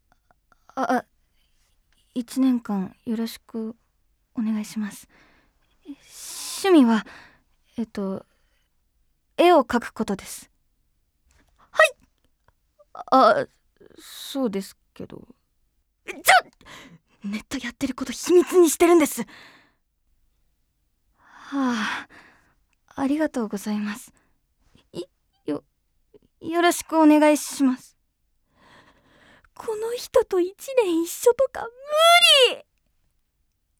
• セリフ03
⑤ネット絵師女の子.wav